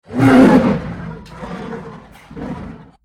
Lion Roaring #2 | TLIU Studios
Category: Animal Mood: Powerful Editor's Choice